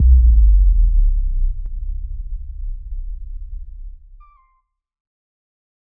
Low End 11.wav